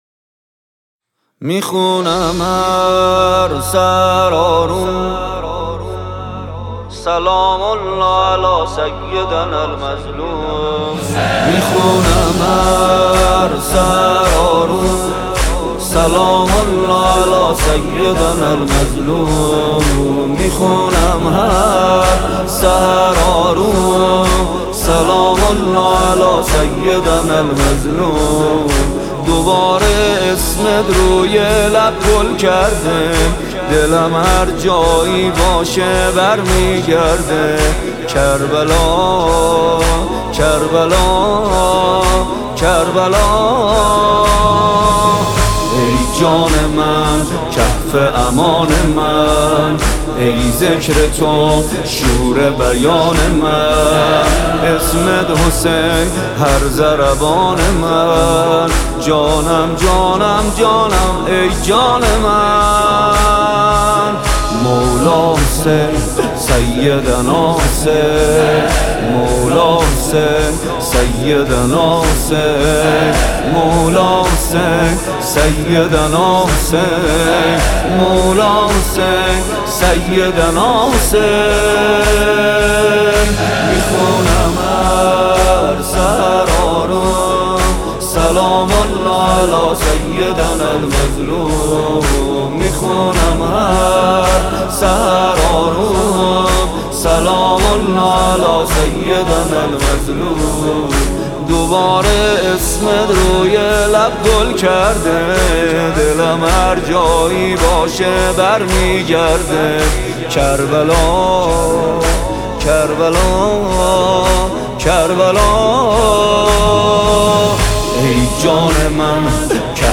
نوحه
مداحی محرم